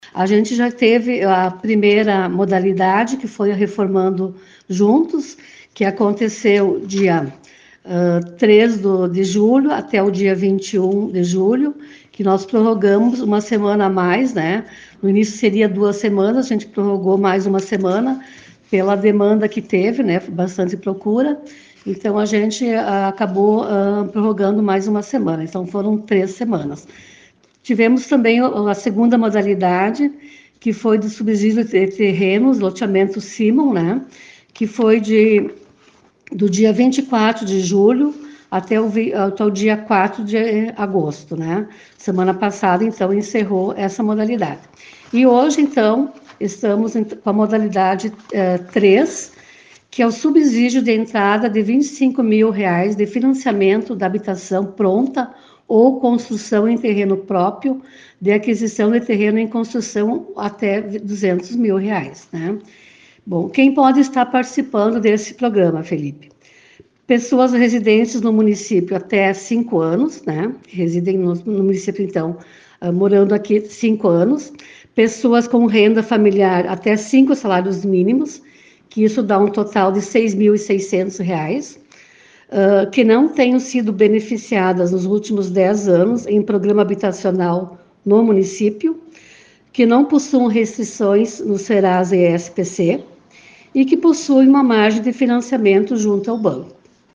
Em entrevista ao Grupo Ceres de Comunicação